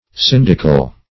Search Result for " syndical" : The Collaborative International Dictionary of English v.0.48: Syndical \Syn"dic*al\, a. 1.